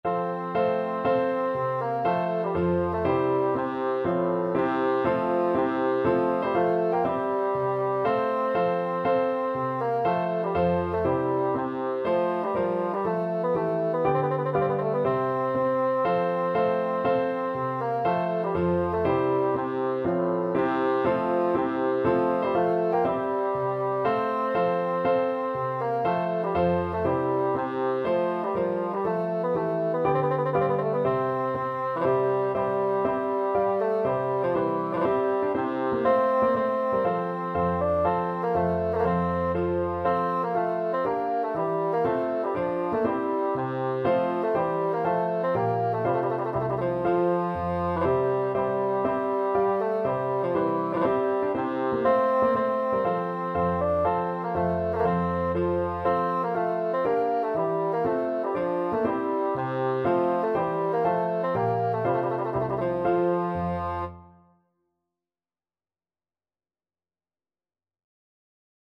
Bassoon
F major (Sounding Pitch) (View more F major Music for Bassoon )
4/4 (View more 4/4 Music)
Allegro (View more music marked Allegro)
Classical (View more Classical Bassoon Music)